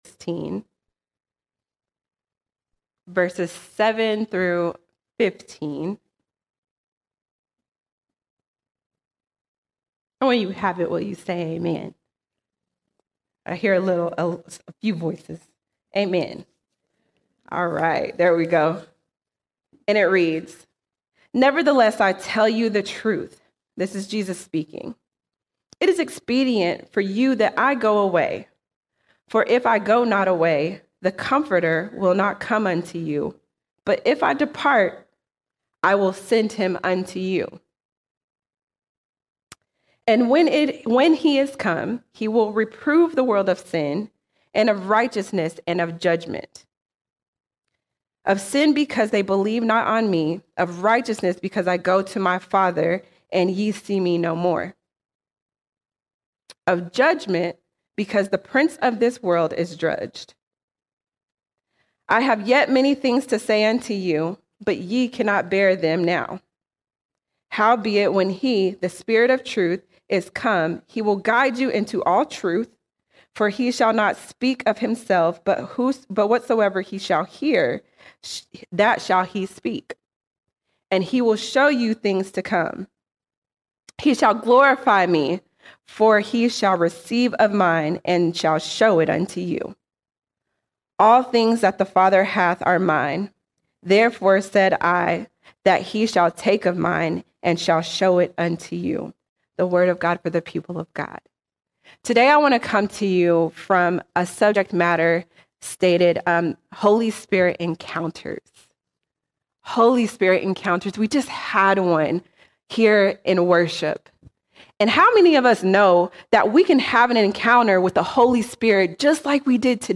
17 February 2025 Series: Sunday Sermons All Sermons Holy Spirit Encounters Holy Spirit Encounters The Holy Spirit is a person who desires to be encountered.